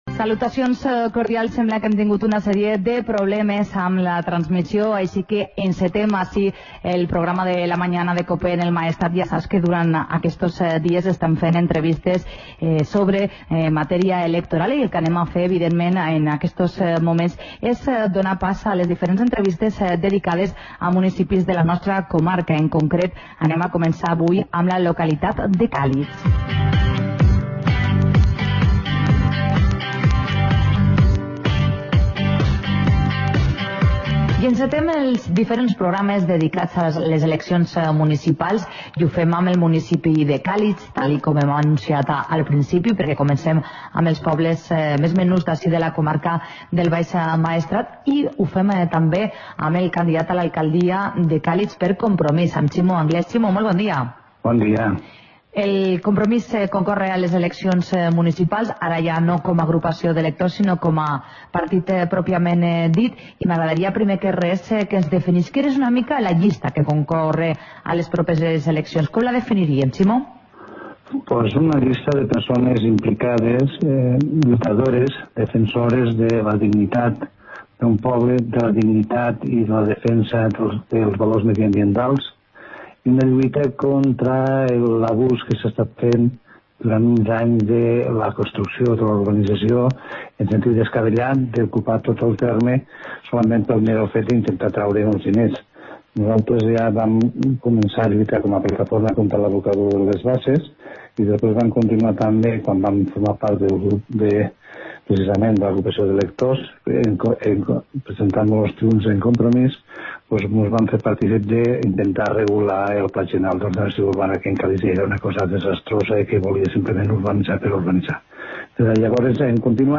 Magazine comarcal del Maestrat